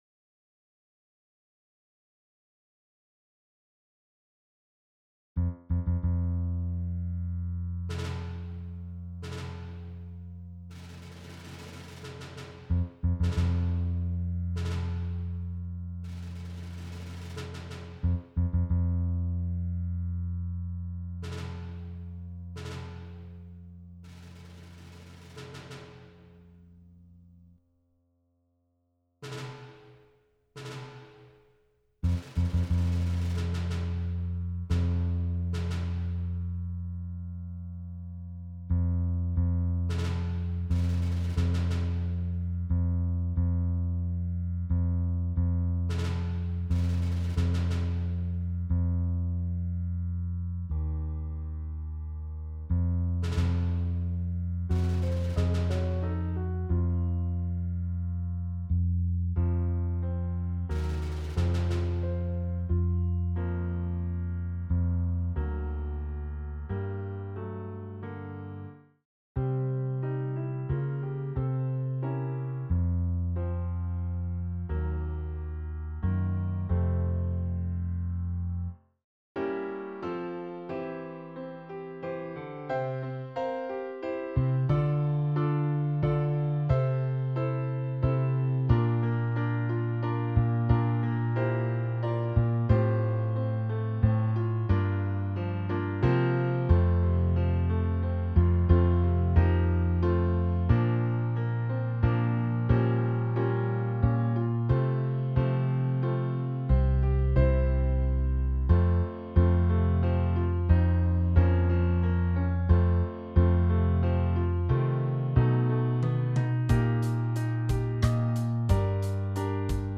Old Rugged Cross Portrait Piano Bass Drums Stem
Old-Rugged-Cross-Portrait-Piano-Bass-Drums-Stem.mp3